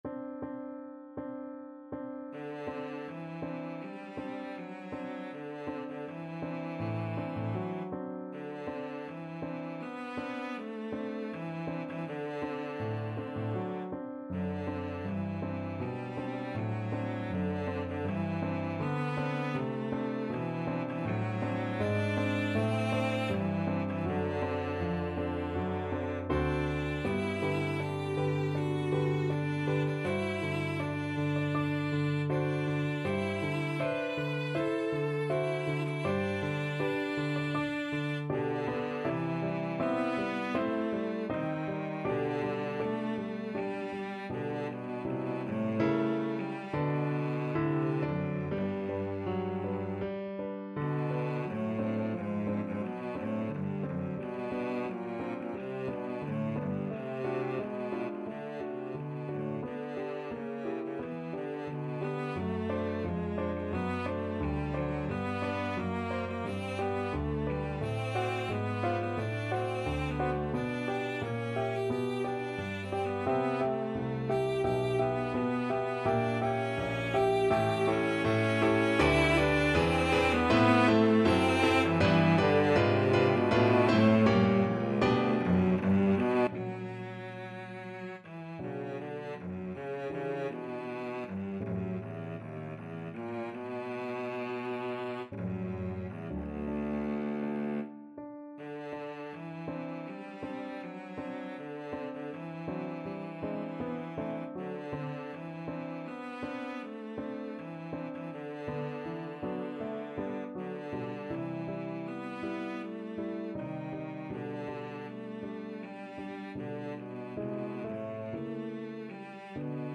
Free Sheet music for Cello
Cello
G major (Sounding Pitch) (View more G major Music for Cello )
Andante espressivo
4/4 (View more 4/4 Music)
Classical (View more Classical Cello Music)